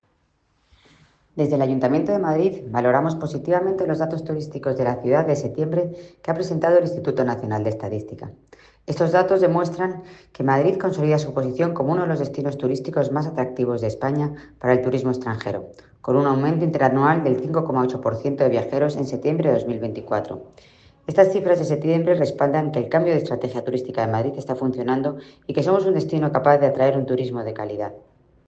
Declaraciones de la concejala delegada de Turismo, Almudena Maíllo, sobre los nuevos datos de turismo: